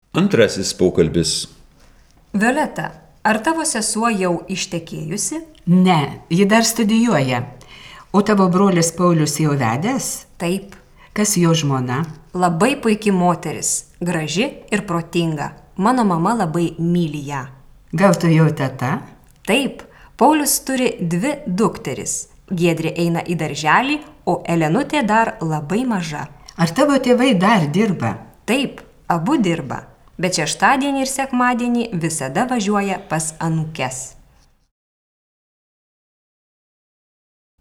03_Dialog_02.wav